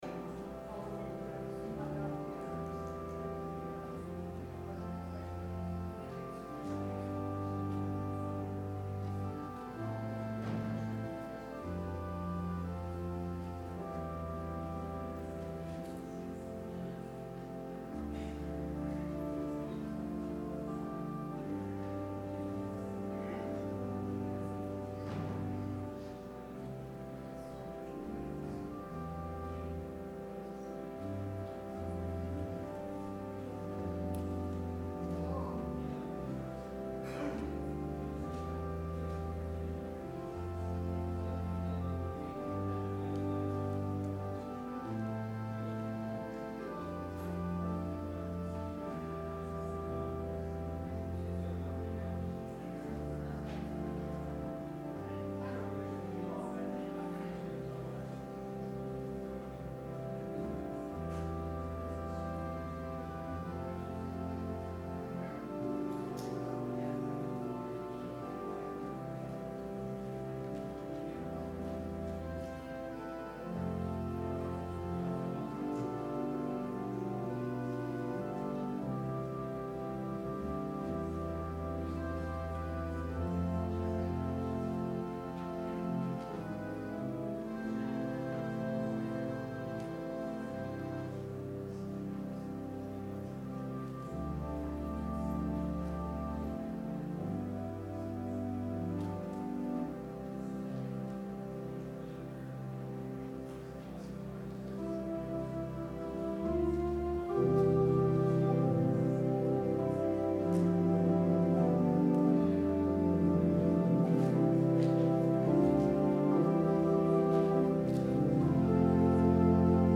Sermon – July 28, 2019